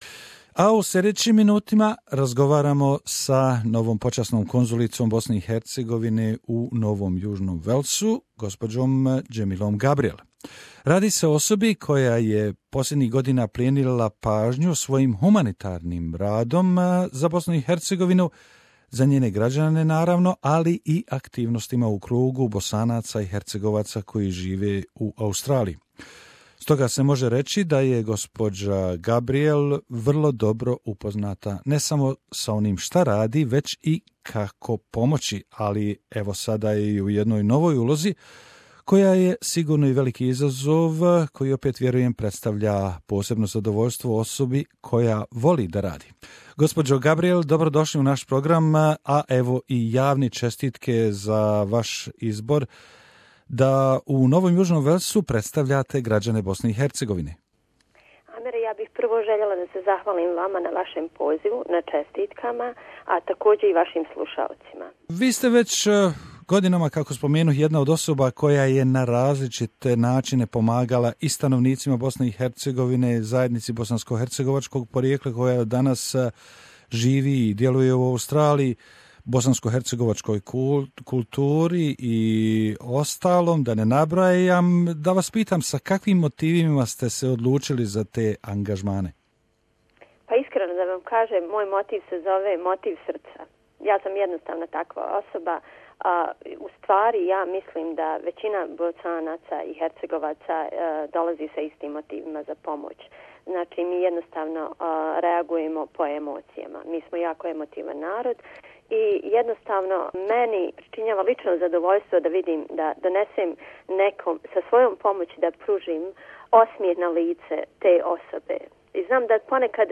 Interview with a new Honorary Consul of Bosnia and Herzegovina in NSW, Mrs. Demila Gabriel